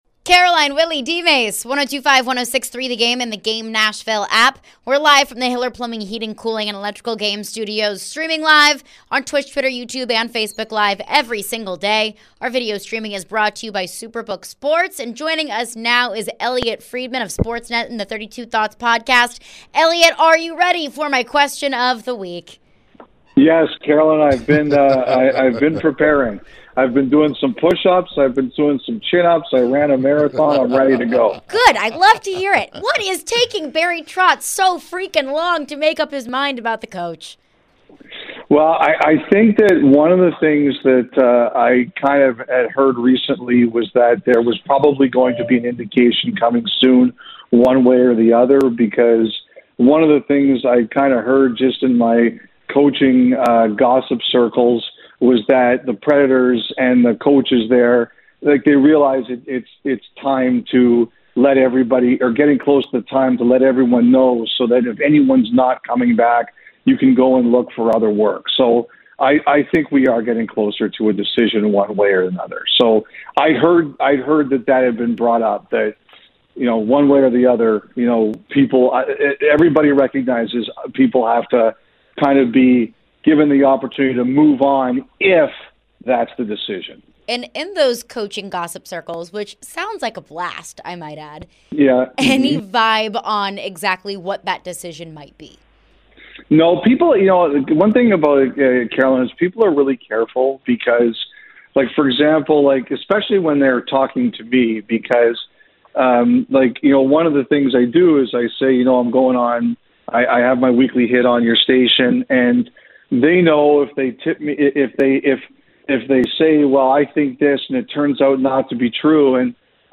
Elliotte Friedman Interview (5-18-23)